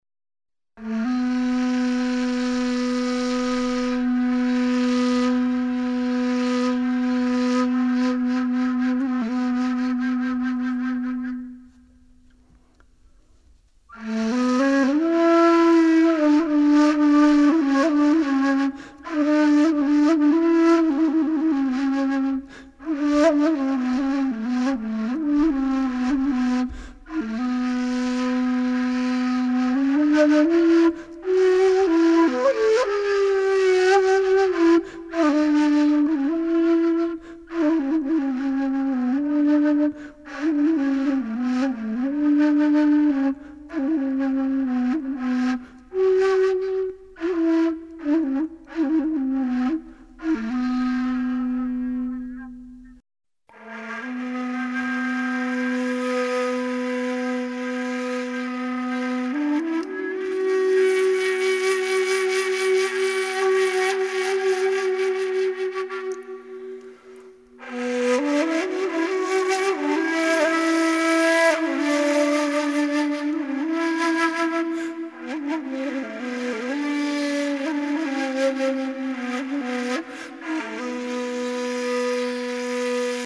Hüseynî Makamı: Barış, sakinlik ve rahatlık hissi verir.
Hüseyni-Makamı.mp3